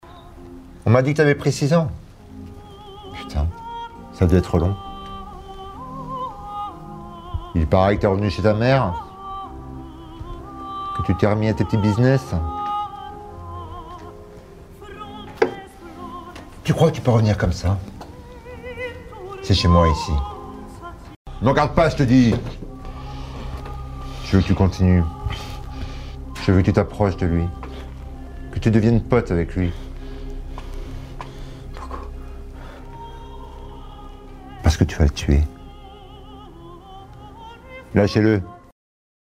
Voix off
Bande son humour